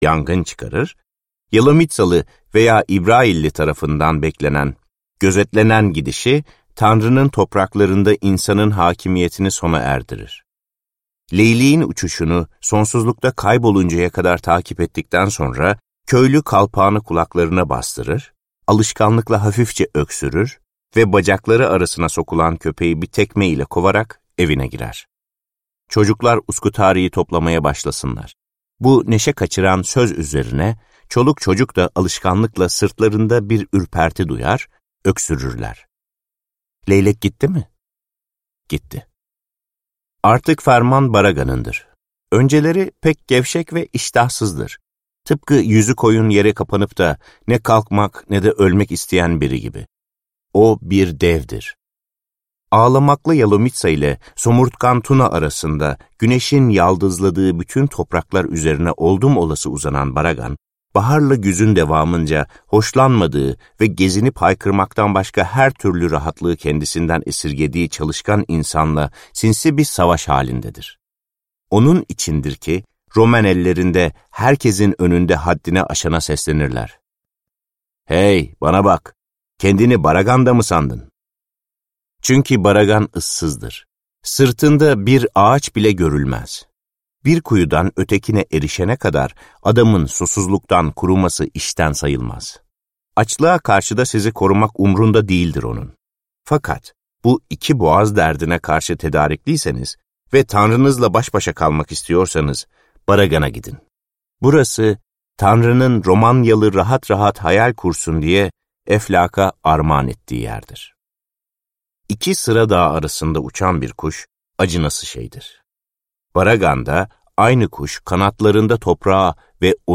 Baragan'ın Dikenleri - Seslenen Kitap